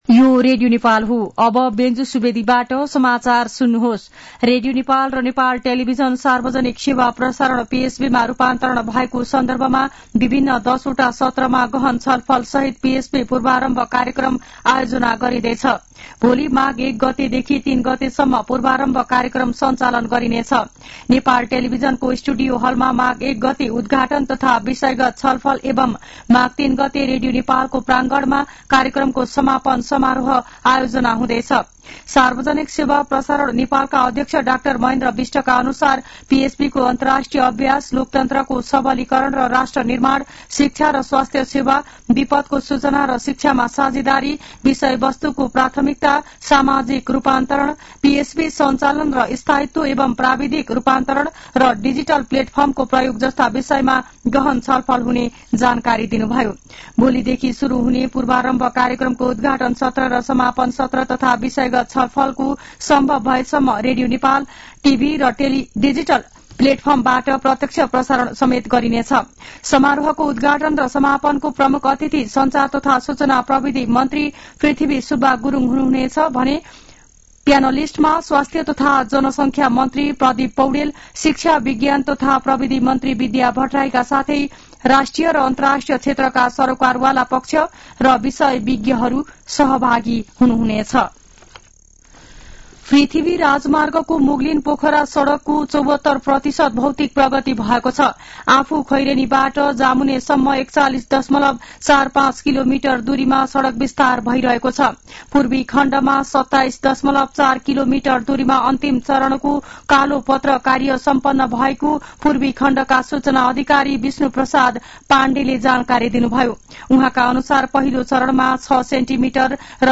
मध्यान्ह १२ बजेको नेपाली समाचार : १ माघ , २०८१